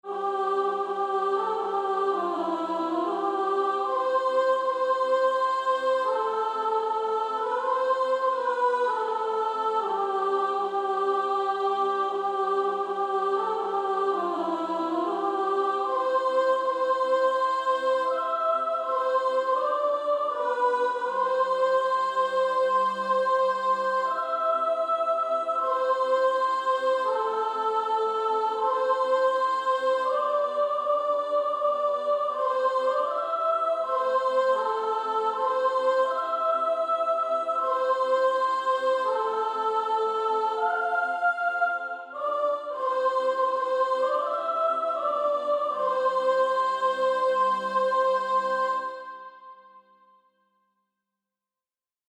Practice then with the Chord quietly in the background.